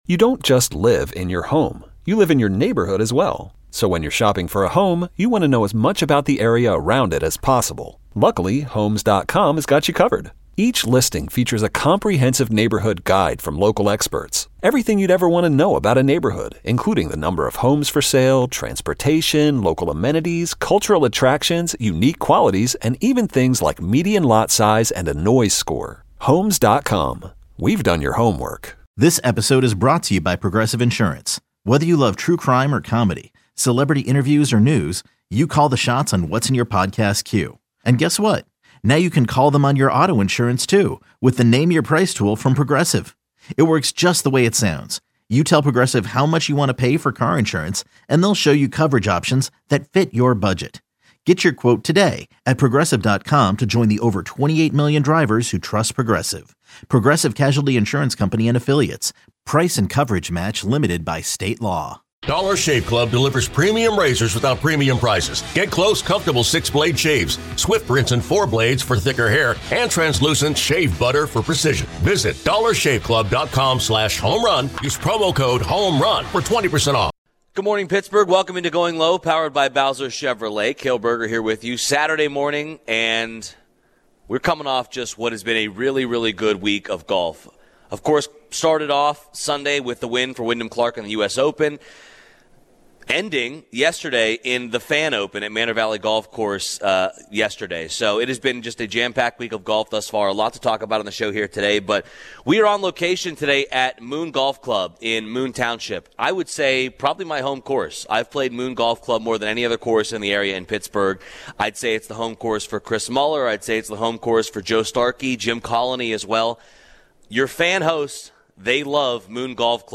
Live from Moon Golf Club Saturday